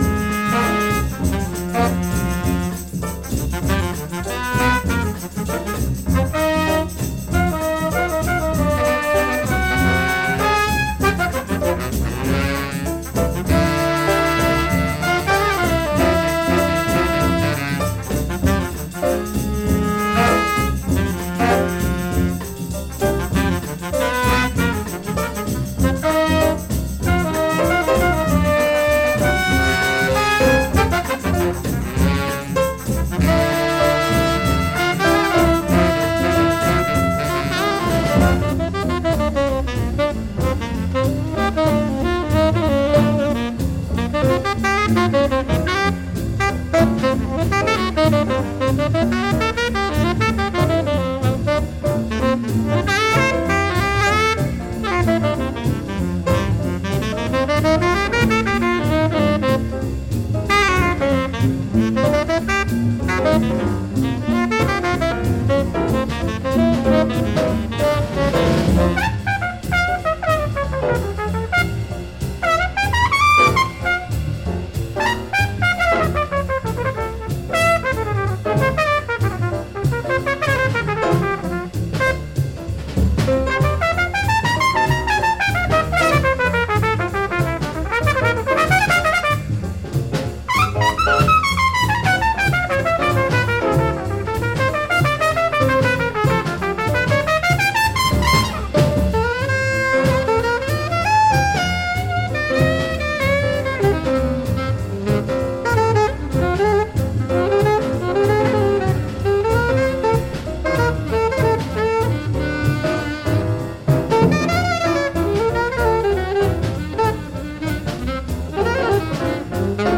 Remastered from the original master tapes.